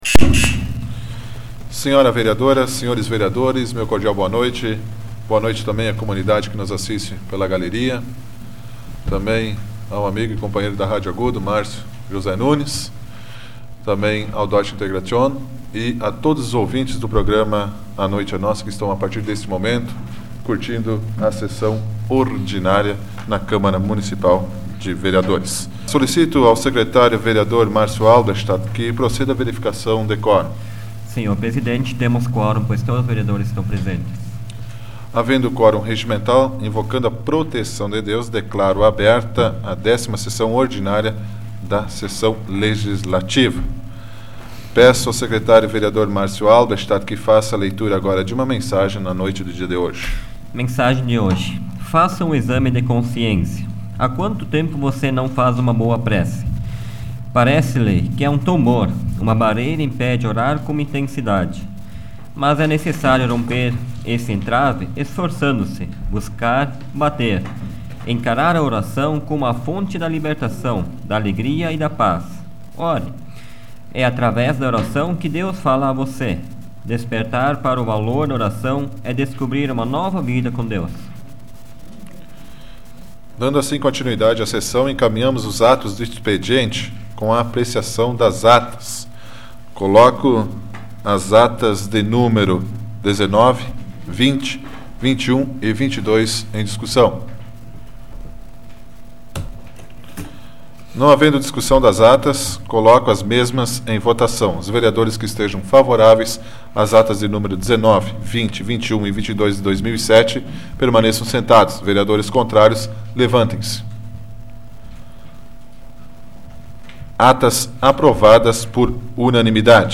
Áudio da 90ª Sessão Plenária Ordinária da 12ª Legislatura, de 18 de junho de 2007